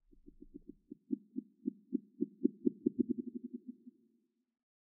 creaking_heart_idle4.ogg